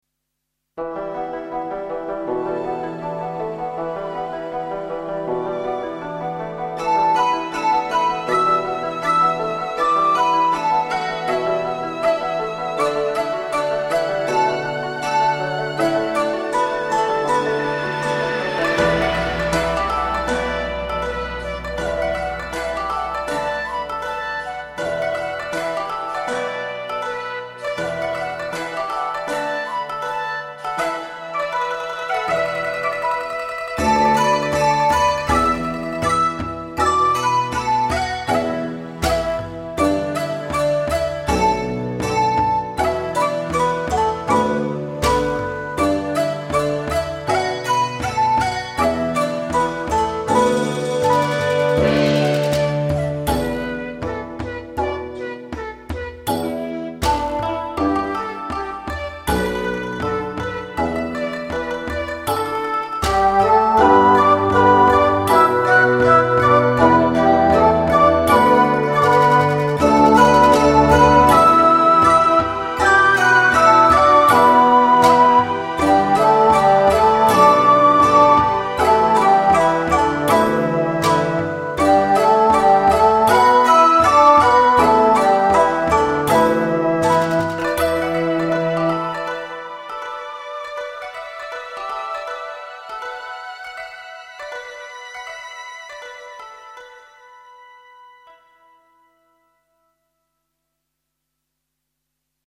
Hierbij een aantal fragmenten op basis van inheemse en folkloristische instrumenten.
Chinese Wall World South-East 1:42 1,5mb 1988 Stukje uit het vervallen project 'Looking For Hidden Treasures'